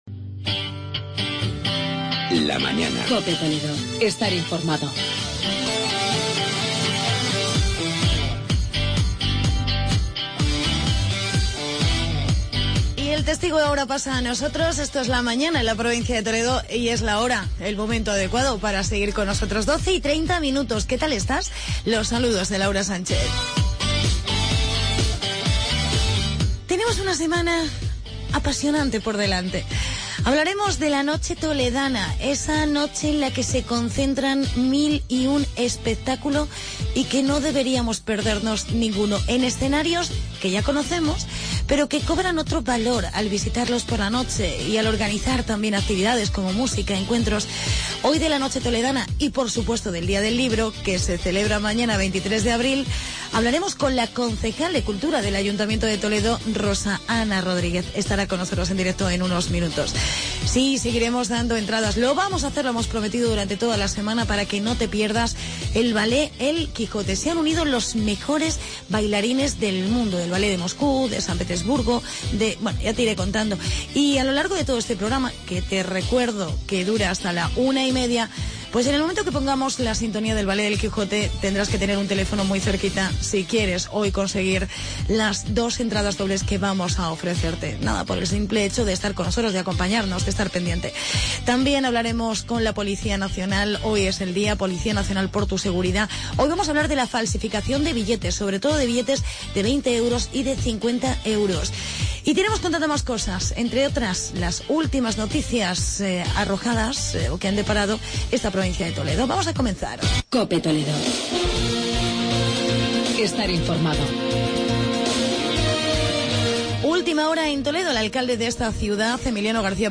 Hablamos del Día del Libro y de "La Noche Toledana" con la concejal Rosana Rodríguez.